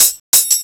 TAMBTRILP1-R.wav